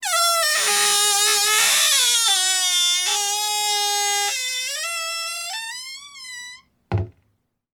Cupboard Close Sound
household
Cupboard Close